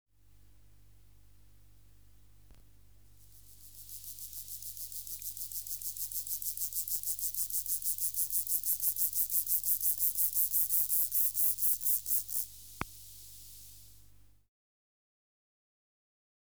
899:6 Chorthippus mollis (112r3) | BioAcoustica
Natural History Museum Sound Archive